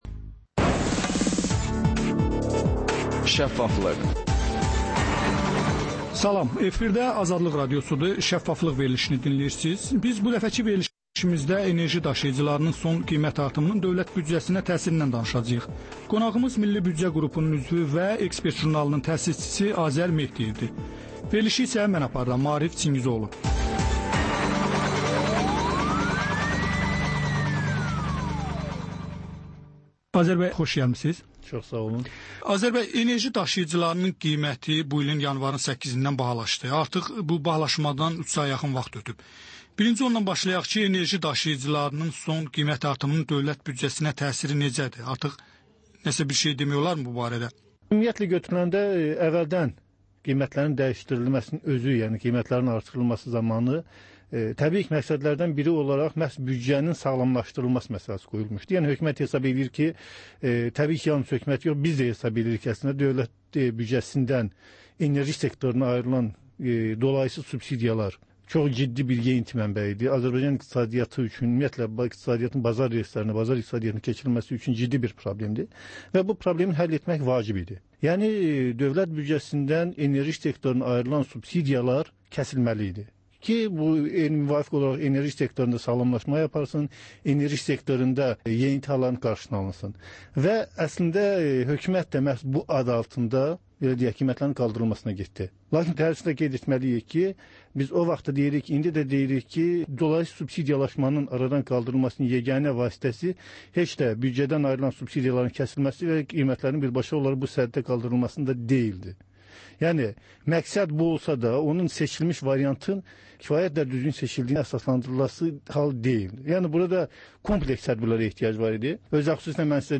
Korrupsiya haqqında xüsusi veriliş.